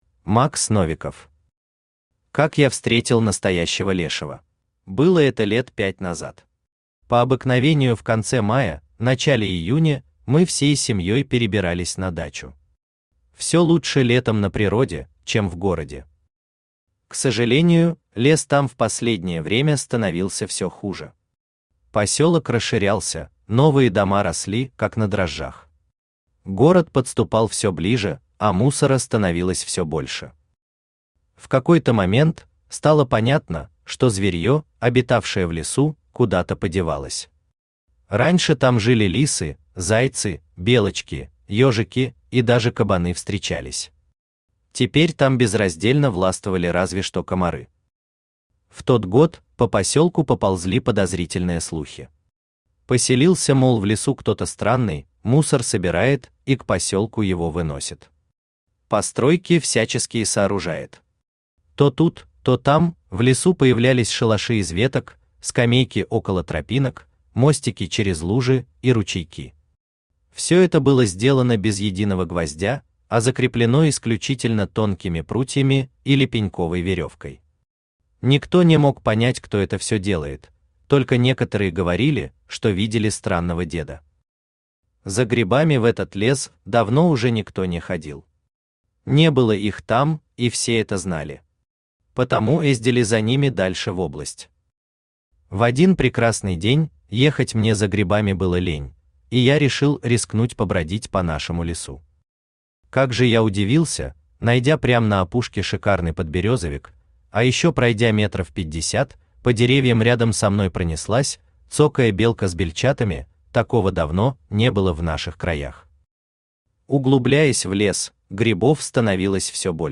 Аудиокнига Как я встретил настоящего лешего | Библиотека аудиокниг
Aудиокнига Как я встретил настоящего лешего Автор Макс Новиков Читает аудиокнигу Авточтец ЛитРес.